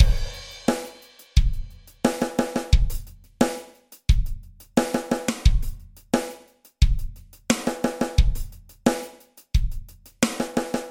KR3 16th Note Rock Beat 88bpm
描述：16th note rock beat at 88 bpm.
标签： 88 bpm Rock Loops Drum Loops 939.72 KB wav Key : Unknown
声道单声道